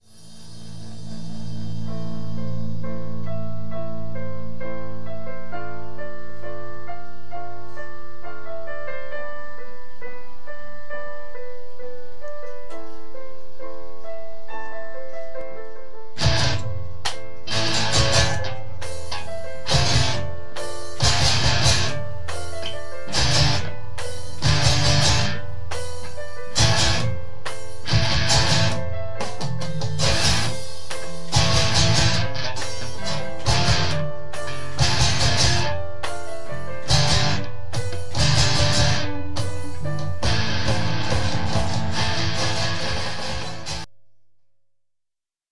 Piano Theme